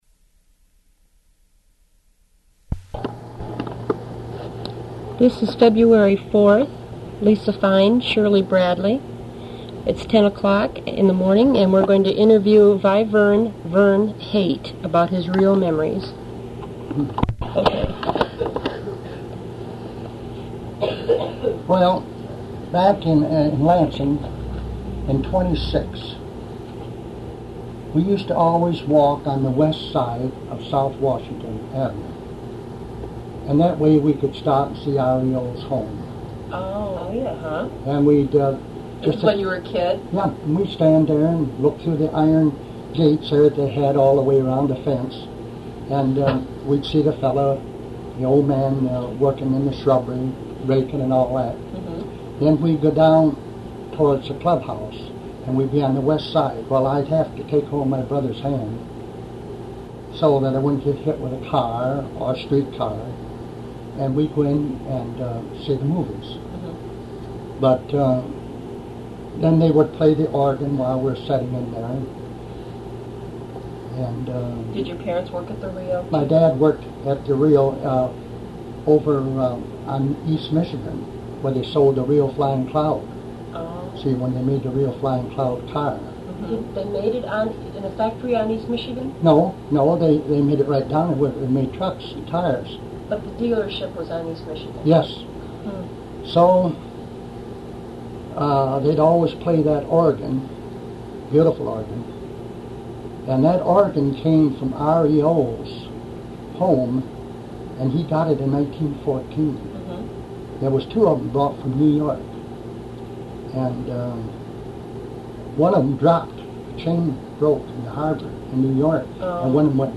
Recorded as part of the REO Memories oral history project.